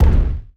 EXPLDsgn_Explosion Impact_05_SFRMS_SCIWPNS.wav